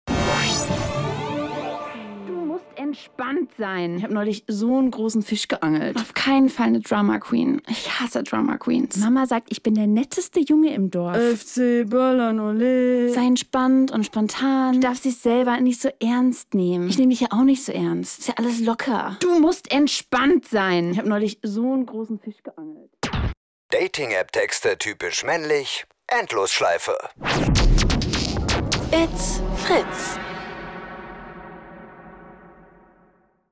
Endlosschleife "Dating-App-Texte" (typisch männlich) | Fritz Sound Meme Jingle